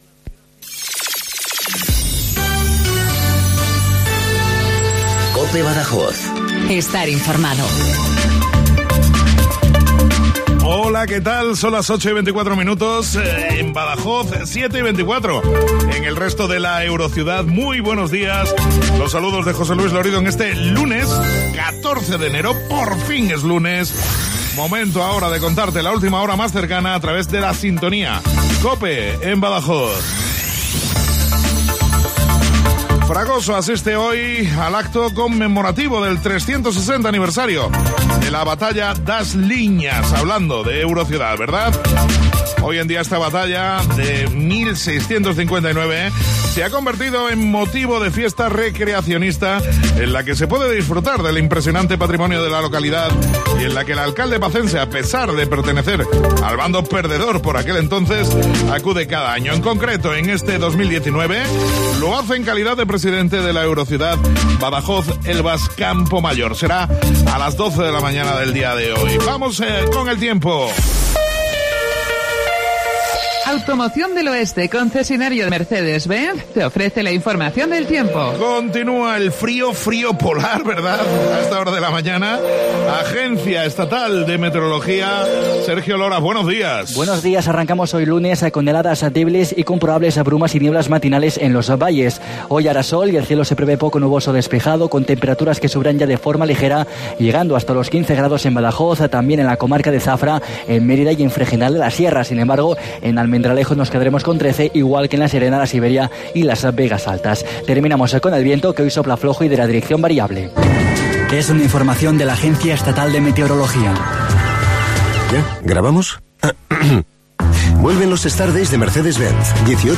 INFORMATIVO LOCAL BADAJOZ 08:24